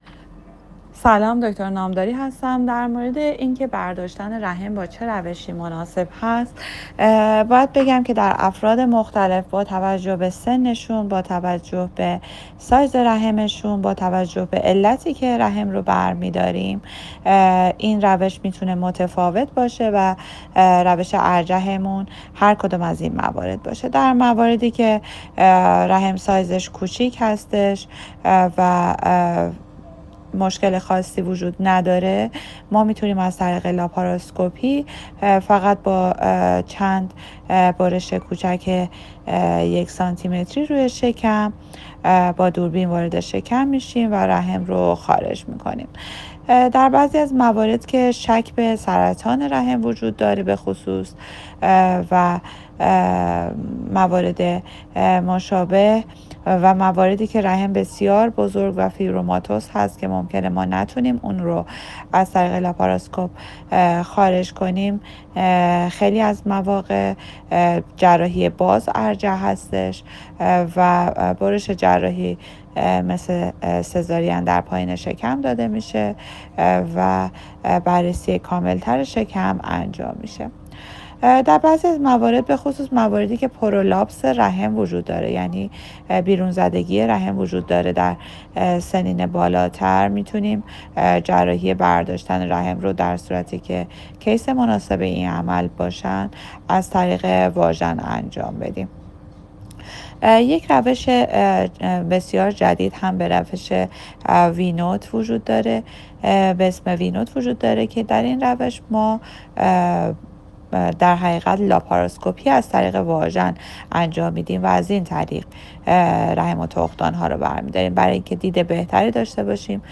مشاوره‌های صوتی